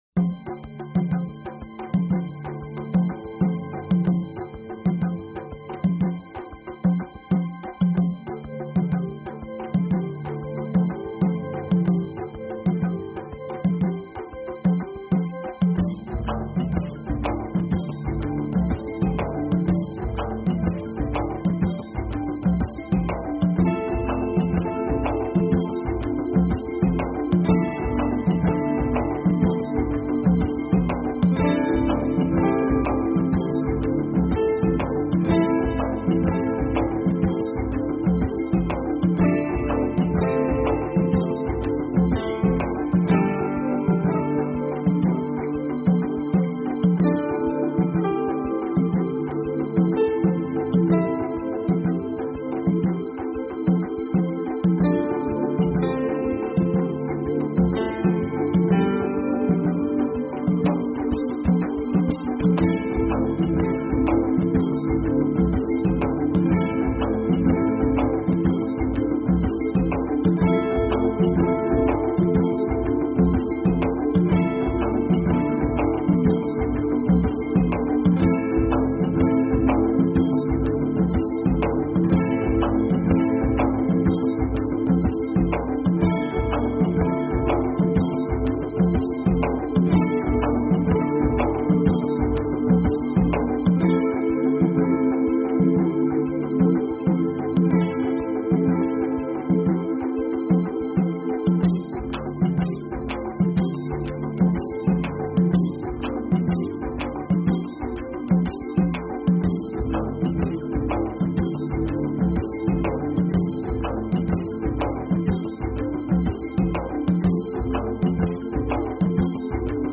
Talk Show Episode, Audio Podcast, DreamPath and Courtesy of BBS Radio on , show guests , about , categorized as
Comedy, music, and a continuous weaving of interviews and story telling (DreamPath style) covering numerous alternative thought topics.